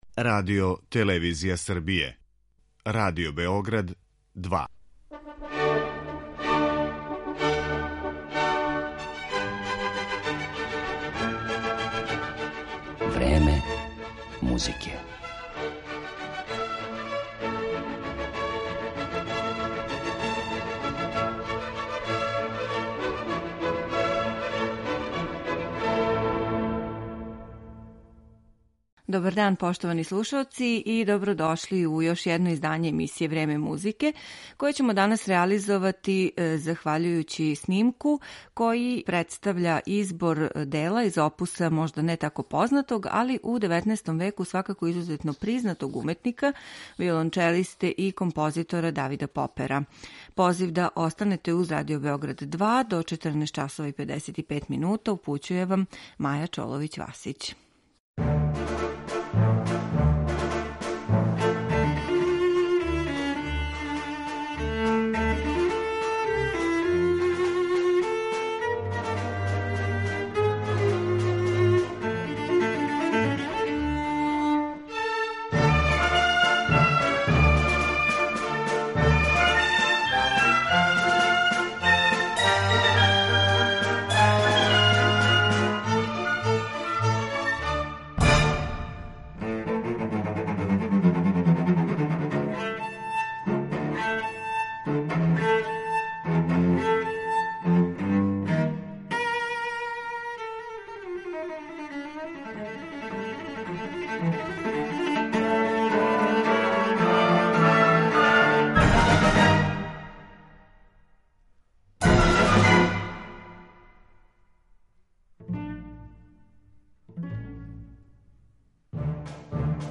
Музика за виолончело чешког композитора Давида Попера
виолончелисткиње